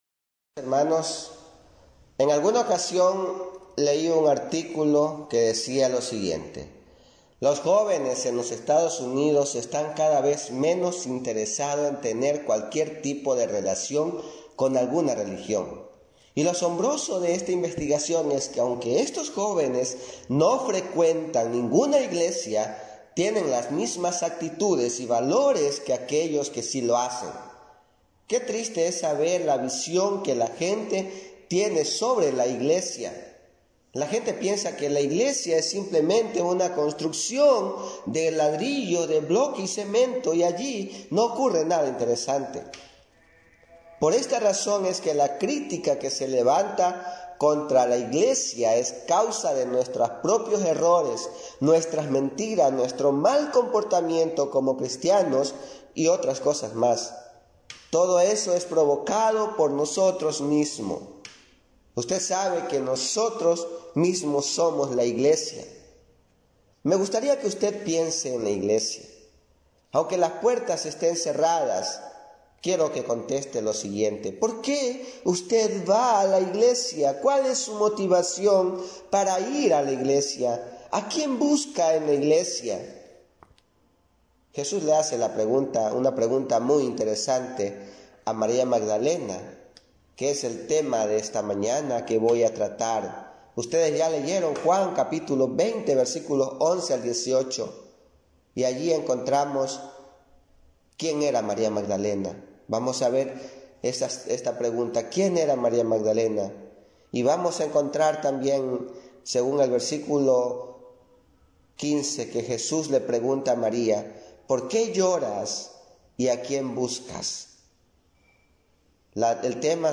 Tipo: Sermón